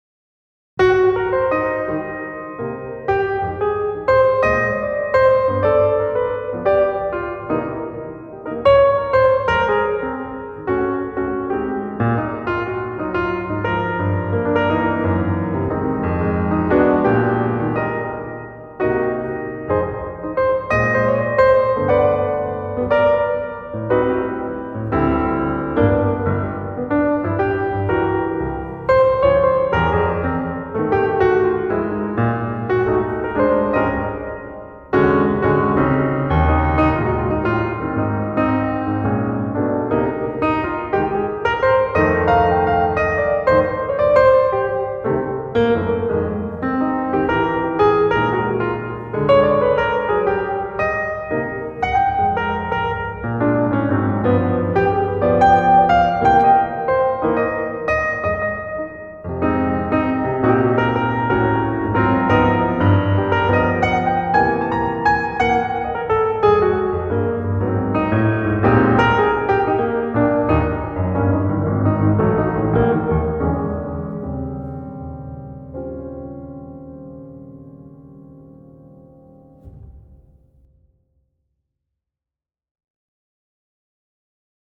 Large Plate Effect Samples
Freeverb3_VST NRev
Preset - Dark Hall x3
LPlate_NRev_Dark_Hall3.mp3